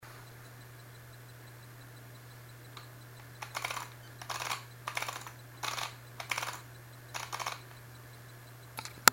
Nahrál jsem si také zvuk (spíše rachot) chodu clony.
ChodclonyJUPITER200mm.MP3